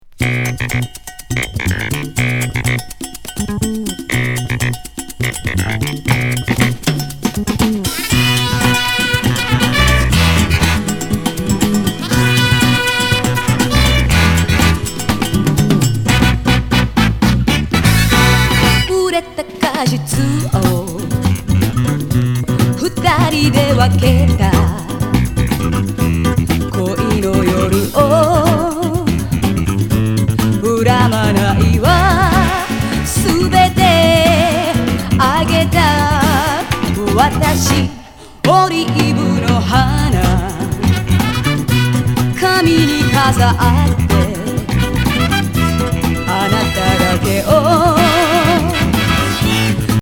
シャンソン・ファンキー・ドＭカバー！